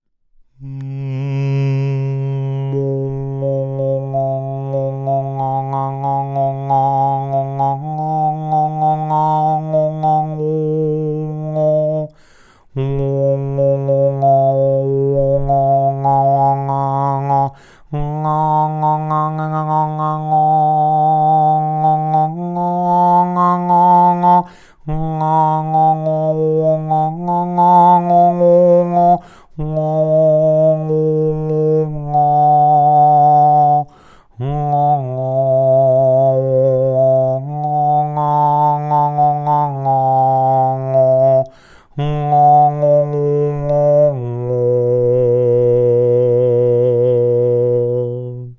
Zweite Verstärkungstechnik: Die Gong-Technik
Hörprobe Obertongesang mit Gong-Technik und Grundtonwechsel
oberton-demo-gongtechnik.mp3